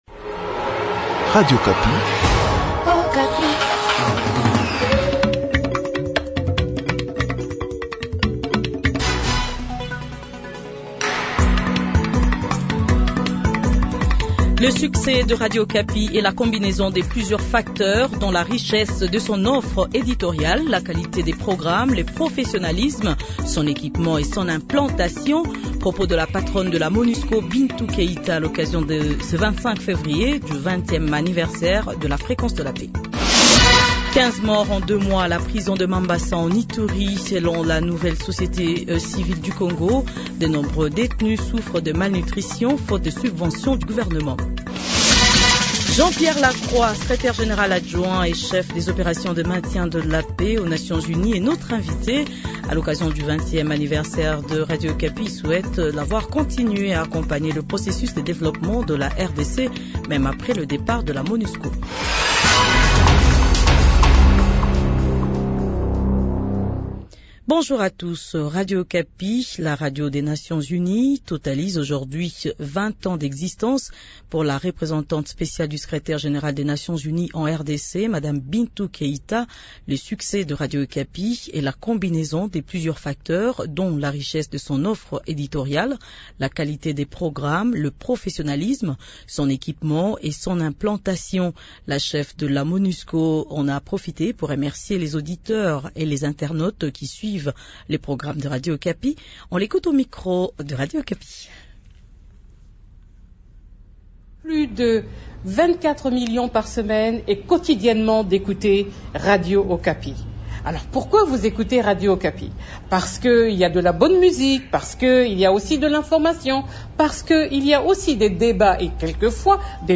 00/ Kinshasa : Message de Madame Bintou Keita, Représentante Spéciale du Secrétaire général des Nations Unies en RDC sur les 20 ans de Radio Okapi.